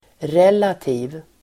Uttal: [r'el:ati:v]